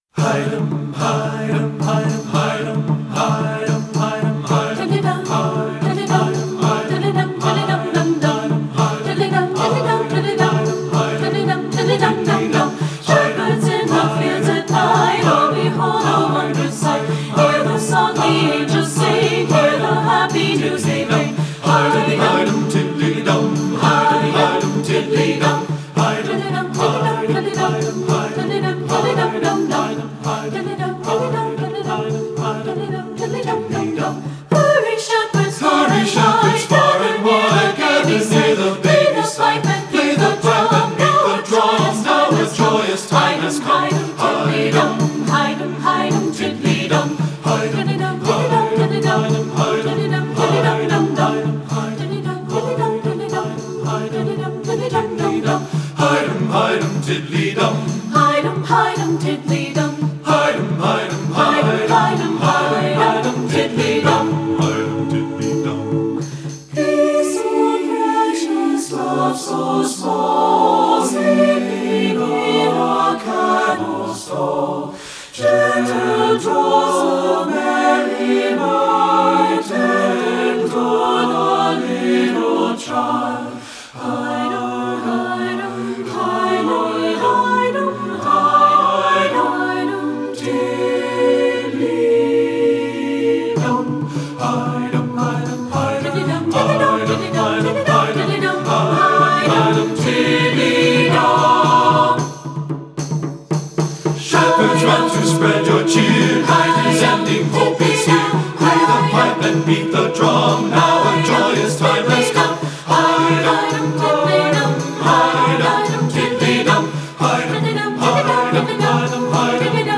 Voicing: SATB and Percussion